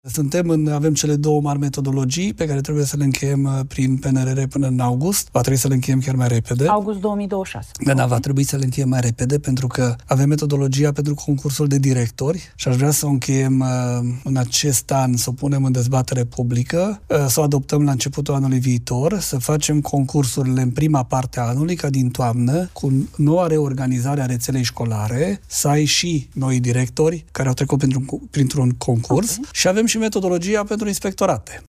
Ministrul Educației, Daniel David: Din toamnă, cu noua reorganizare a rețelei școlare să avem și noii directori care au trecut prin concurs